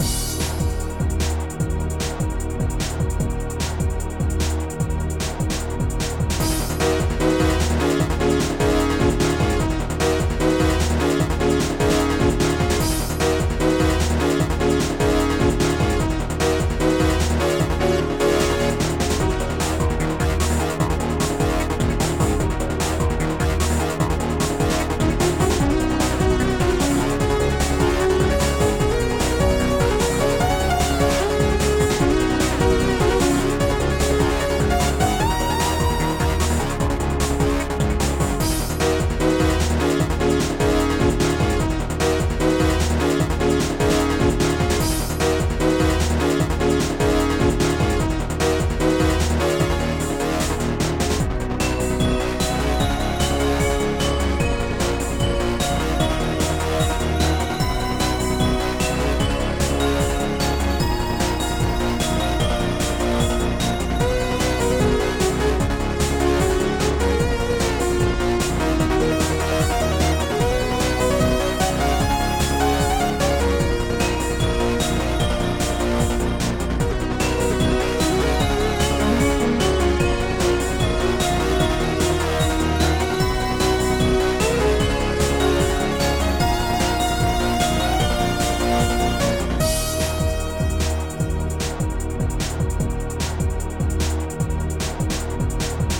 Dist Guitarloop
Ambient Power bells
Bassdrum 1
TR909 Snaredrum
Anlogpad
Crash Cym